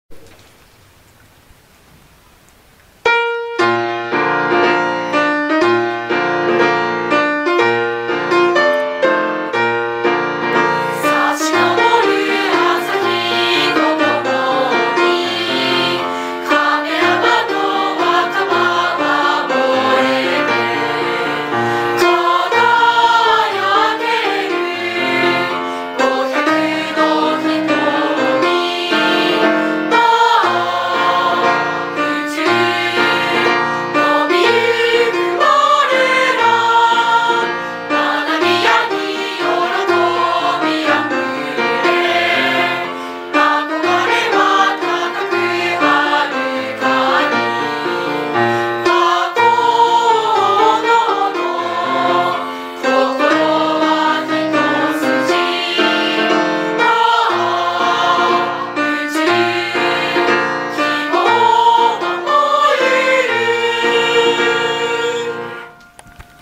校歌 『 学園の歌